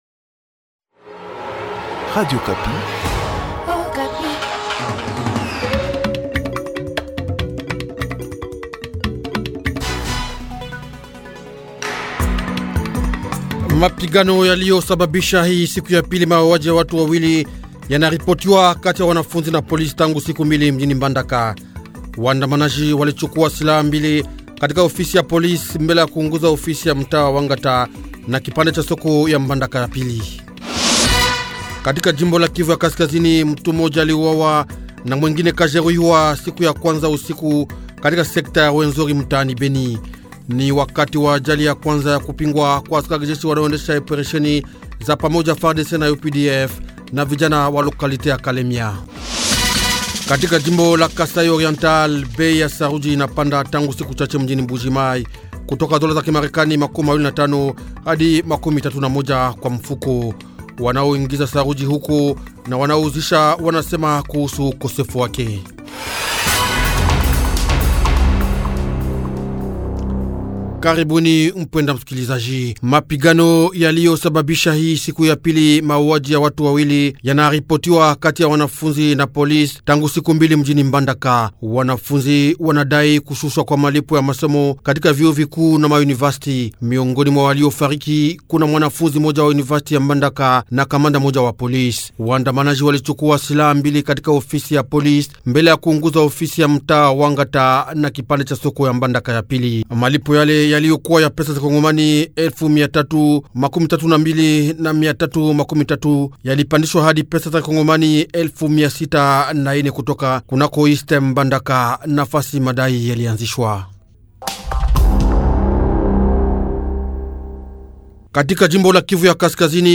Journal Swahili